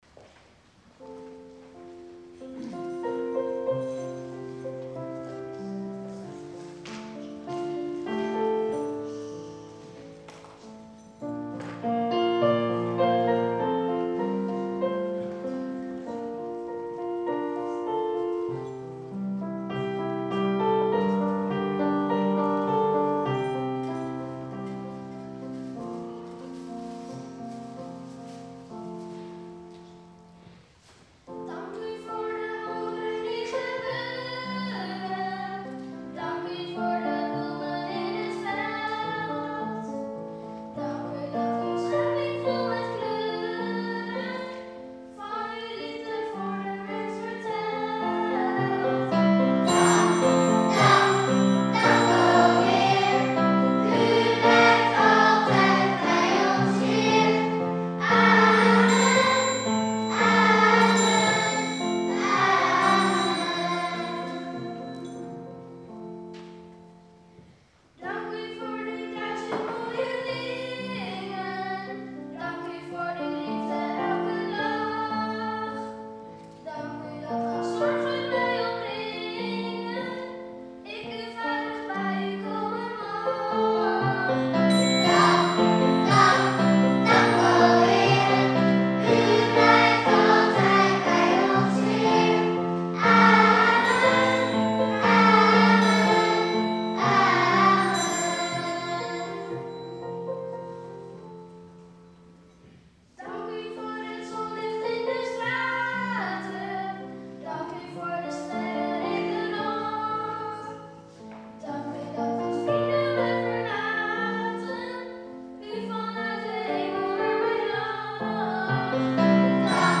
Nicolaas kinderkoor